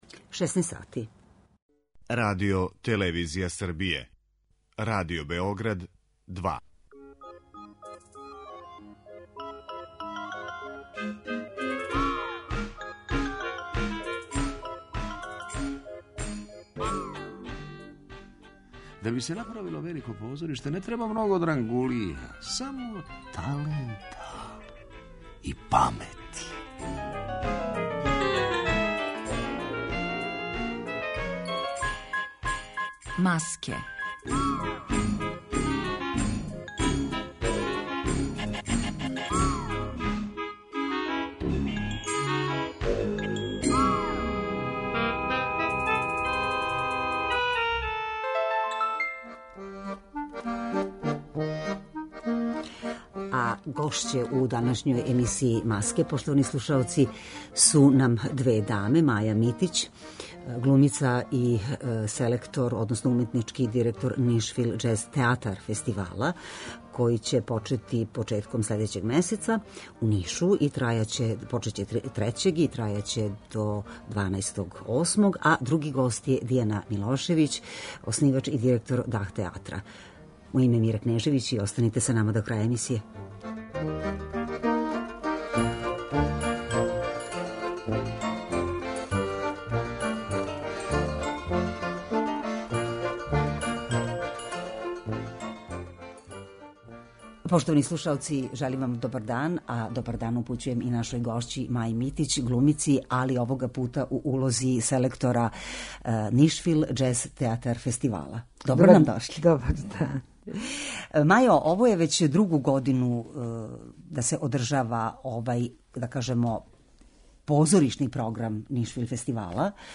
Емисија о позоришту